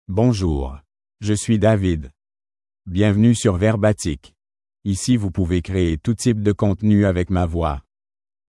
David — Male French (Canada) AI Voice | TTS, Voice Cloning & Video | Verbatik AI
David is a male AI voice for French (Canada).
Voice sample
David delivers clear pronunciation with authentic Canada French intonation, making your content sound professionally produced.